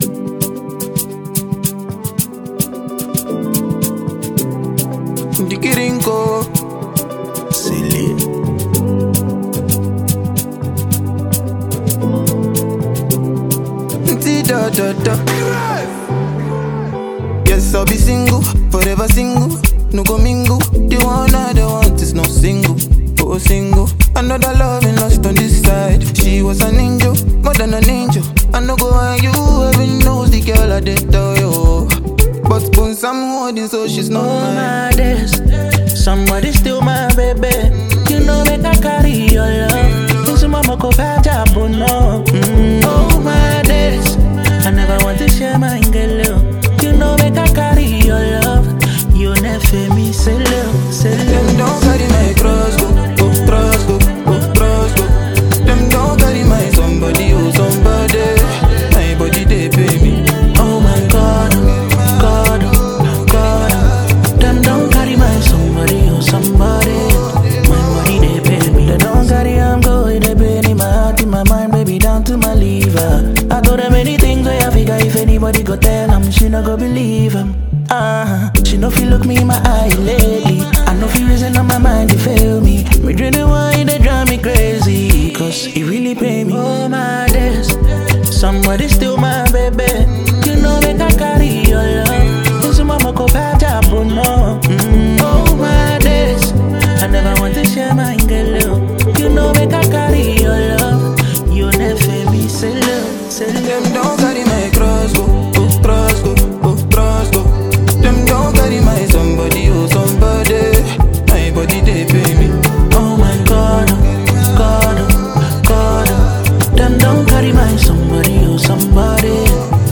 Ghanaian singer and song writer